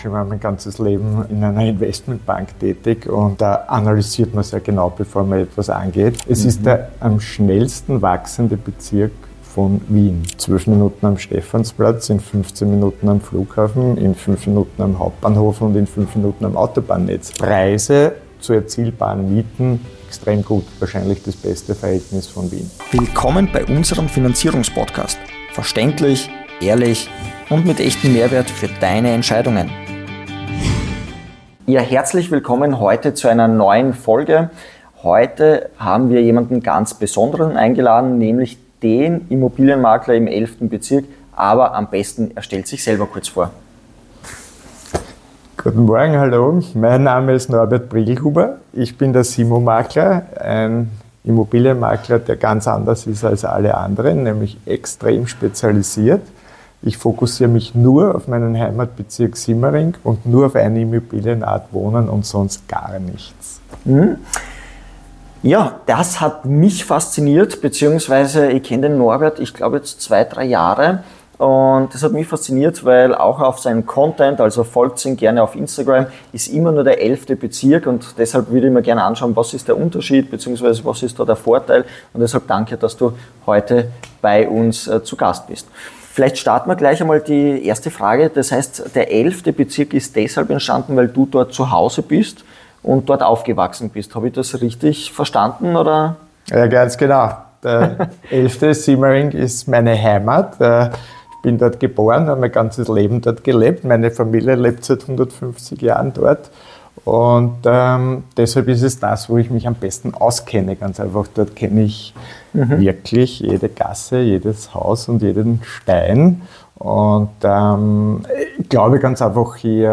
In dieser Folge von Fix & Variabel – der Finanzierungspodcast sprechen wir mit einem ausgewiesenen Simmering-Spezialisten darüber, warum Fokus und Spezialisierung im Immobilienmarkt immer wichtiger werden. Wir beleuchten, warum Simmering in den letzten Jahren stark gewachsen ist, welche Vorurteile dem Bezirk oft im Weg stehen und warum gerade hier das Preis-Miet-Verhältnis außergewöhnlich attraktiv ist.